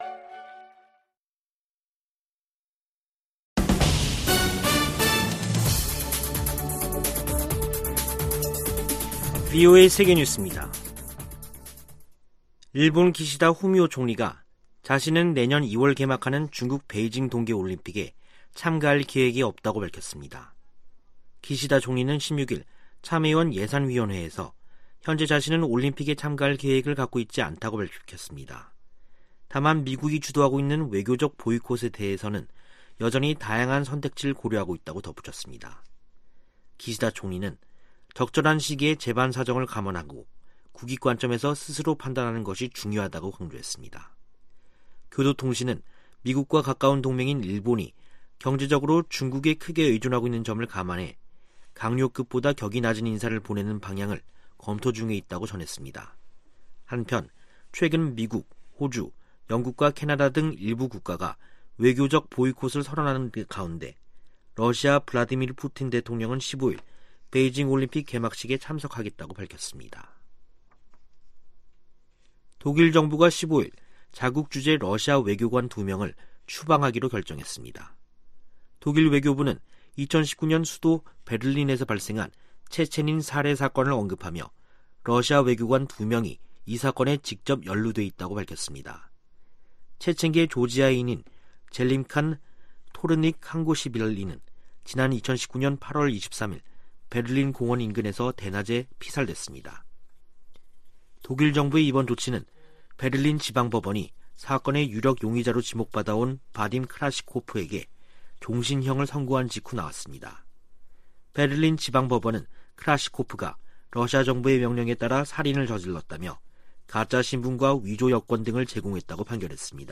VOA 한국어 간판 뉴스 프로그램 '뉴스 투데이', 2021년 12월 16일 3부 방송입니다. 유엔 안보리가 올해도 북한 인권 관련 비공개 회의를 개최한 가운데, 일부 이사국들이 북한 정권의 인권유린 실태를 비판했습니다. 미 상원이 국방수권법안을 가결함에 조 바이든 대통령 서명만 거치면 효력을 갖습니다. 신종 코로나바이러스 감염증 사태가 북한에 두고 온 가족들에게 생활비를 보내 온 한국 내 탈북민들의 부담을 높이고 있습니다.